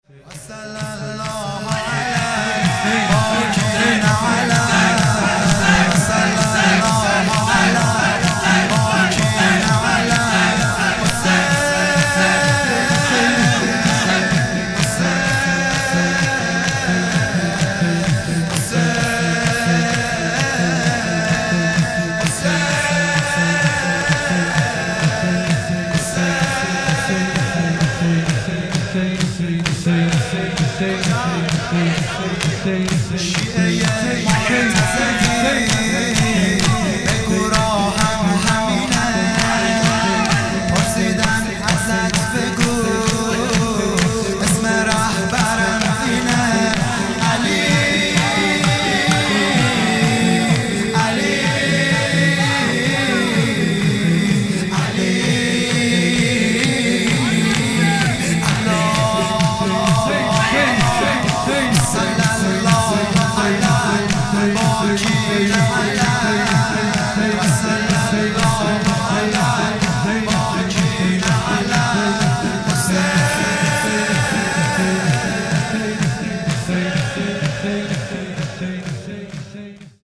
شور
و صل الله علی|جلسه هفتگی ۱۷ مهر ۹۷